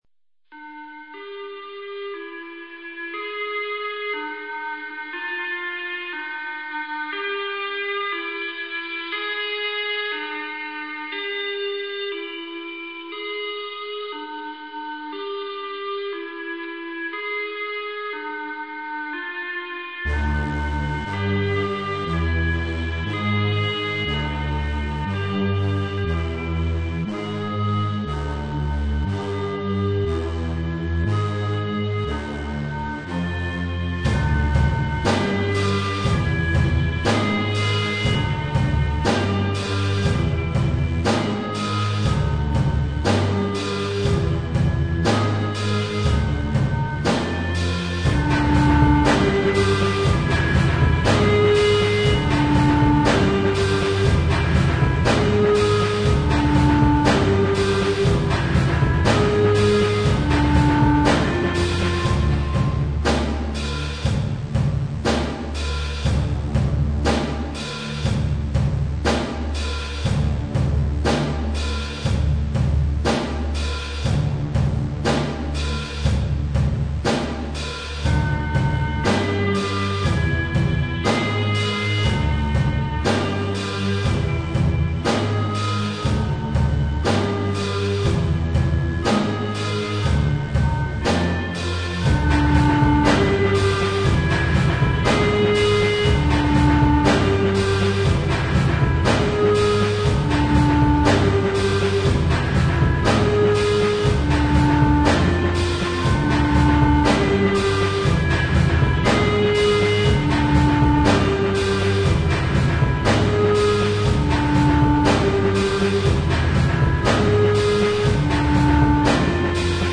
Recollections, remastered and reworked.
Overall? Dreamy.